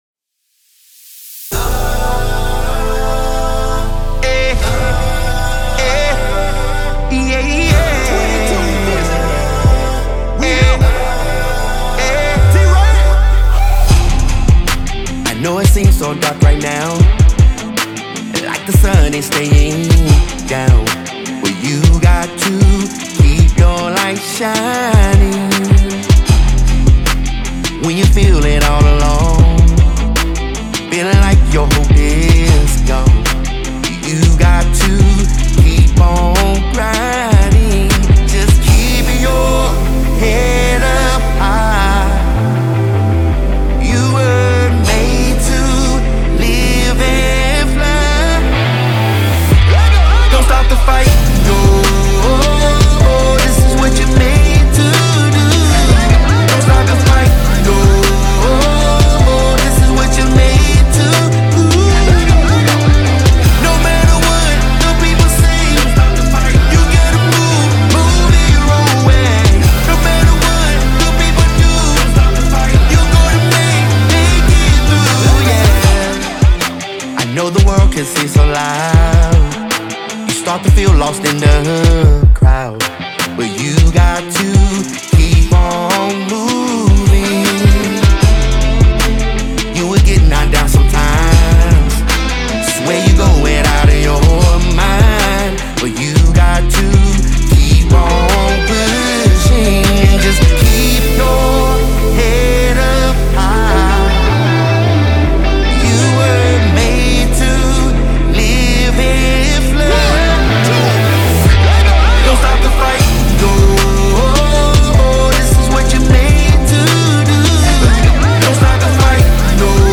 Gospel Firestarter.